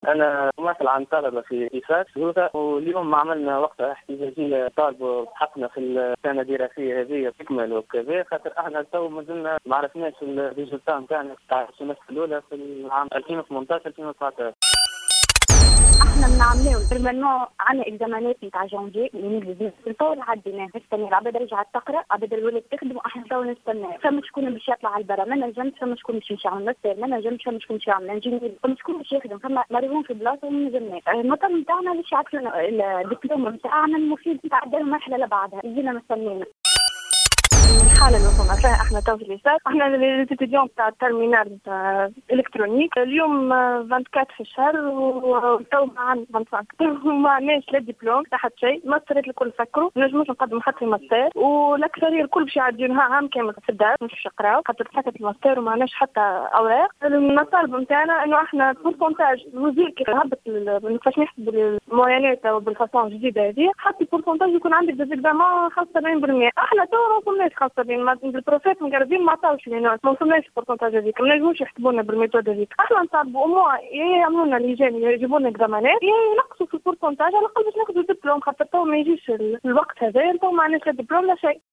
وأكد عدد من طلبة المعهد للجوهرة أف أم، أنه قد تعذر عليهم إلى اليوم الحصول على شهاداتهم بسبب عدم اجتيازهم لامتحانات السداسي الأول للسنة الجامعية المنقضية 2018-2019، على خلفية إضراب منظوري اتحاد الأساتذة الجامعيين الباحثين التونسيين "إجابة".